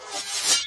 grasping_hands_end.ogg